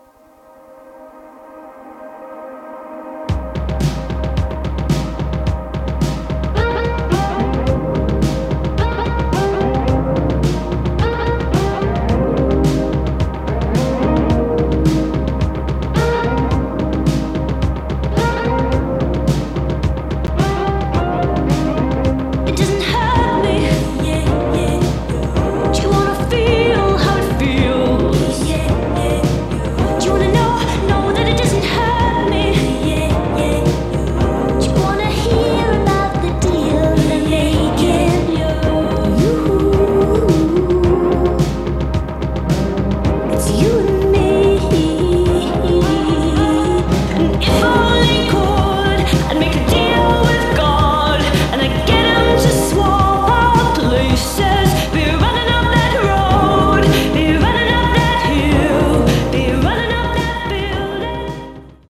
Track System: 4-track, 2-channel stereo
Technics-RS-M205_Test-Recording.mp3